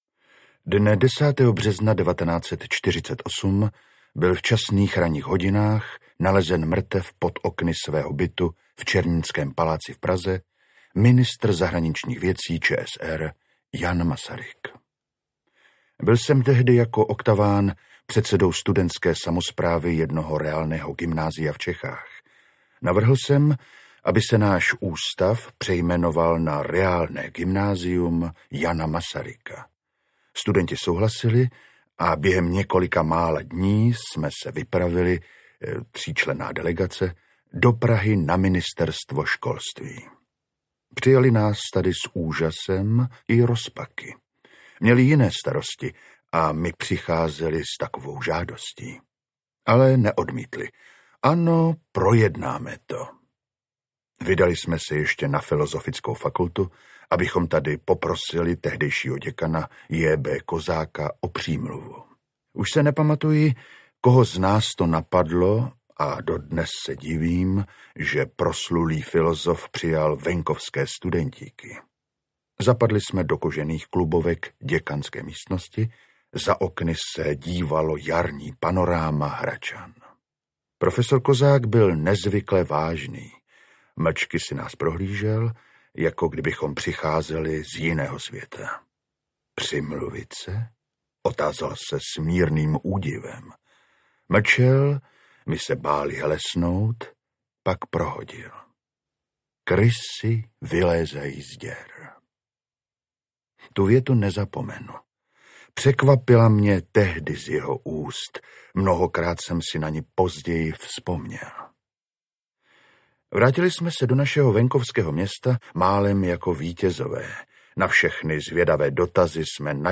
Milada Horáková: justiční vražda audiokniha
Ukázka z knihy